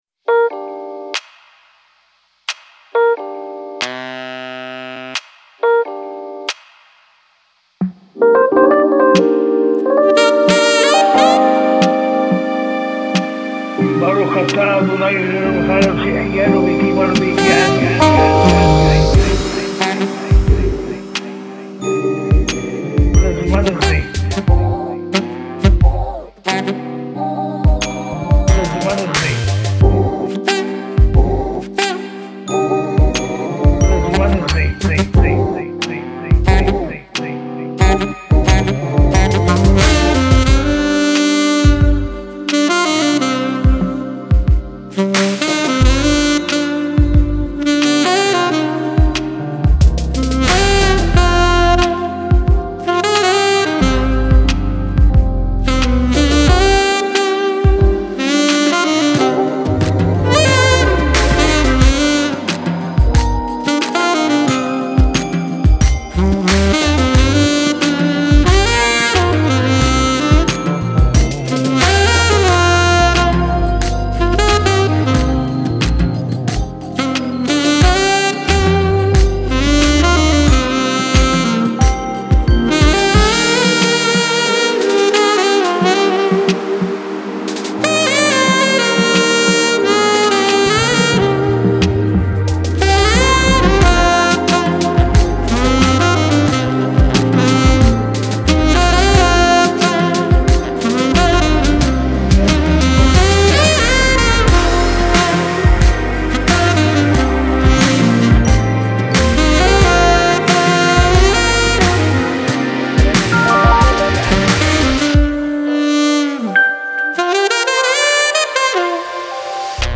בסינגל אינסטרומנטלי
בעיבודי סמות' ג'אז ומקצבים חדשים בהשארת מוזיקת עולם עכשווית.
מתוך הקלטה היסטורית מאירוע משפחתי.
כלי נשיפה